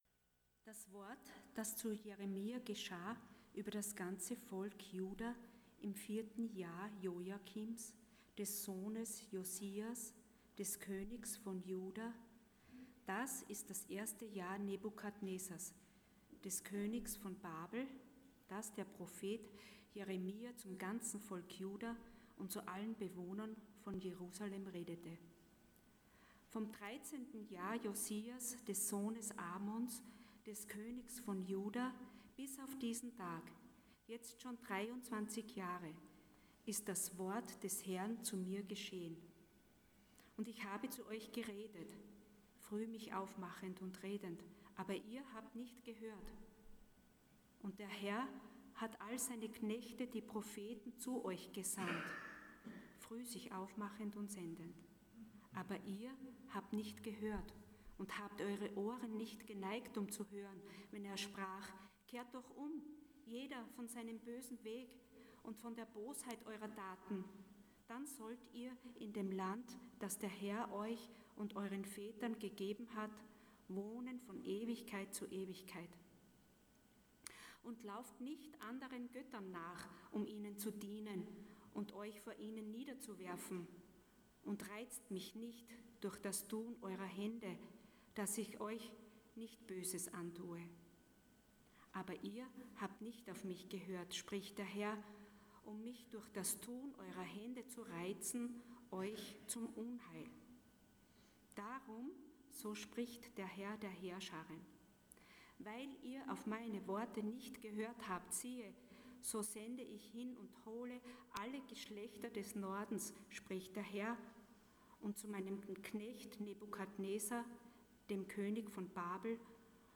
Der große Plan Passage: Jeremiah 25:1-14 Dienstart: Sonntag Morgen %todo_render% Rebellion und die Folgen « Ein ewiges Haus Wer ist Jesus?